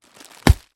Звуки хруста чипсов
Все звуки записаны в высоком качестве и доступны бесплатно.
Звук Мешок с чипсами взрывается (не громко) (00:01)